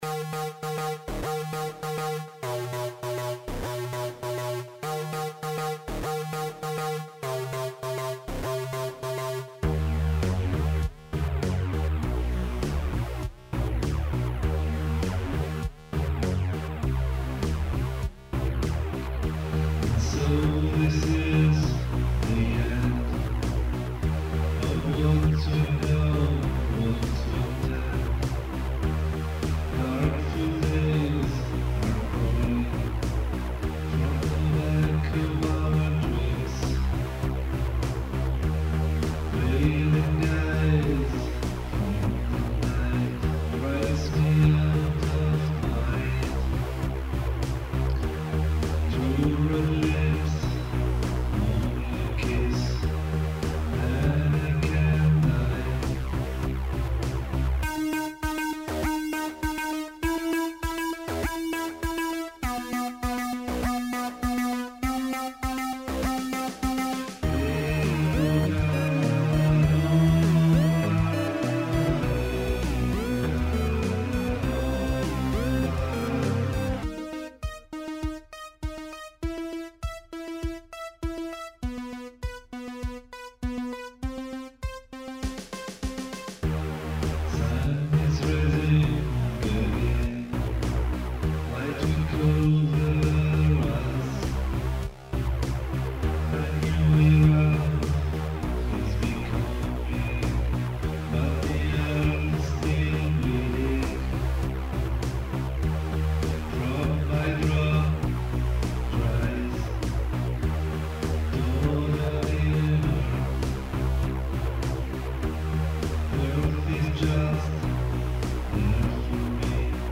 Here you can find the Low Fi version of our songs.
Please note they are only low fi semples.